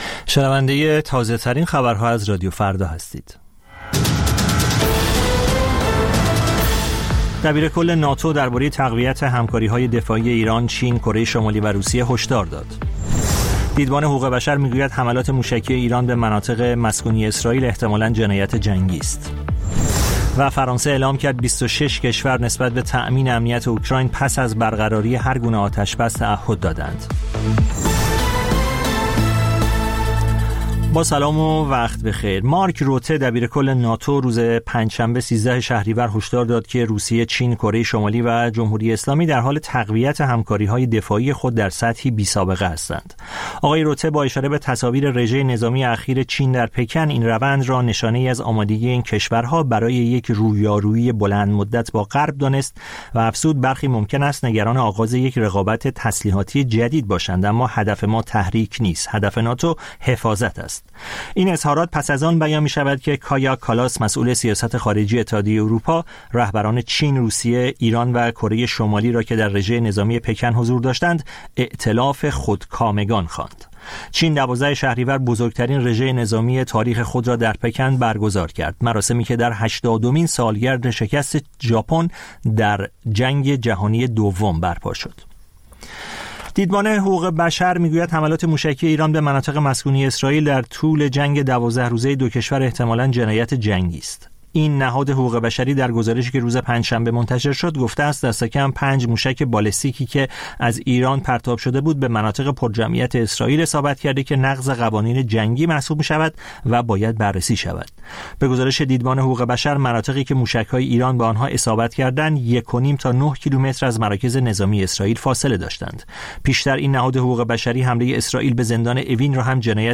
سرخط خبرها ۰۰:۰۰